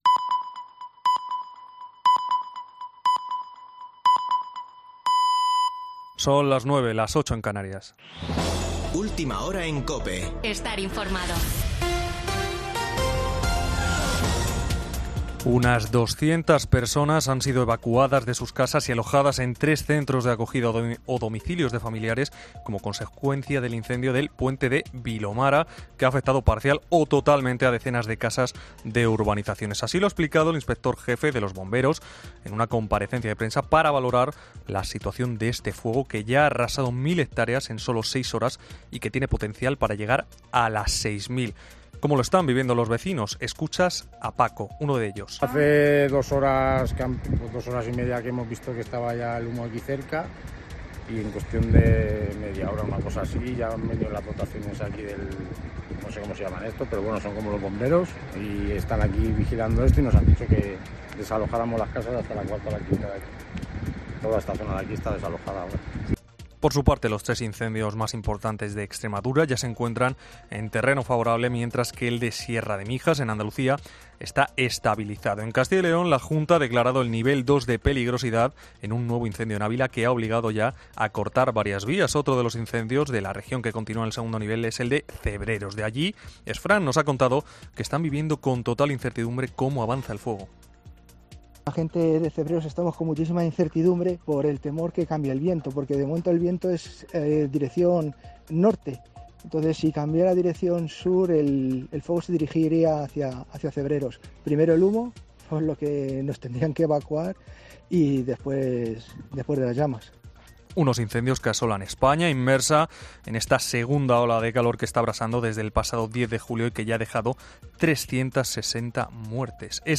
Boletín de noticias de COPE del 17 de julio de 2022 a las 21:00 horas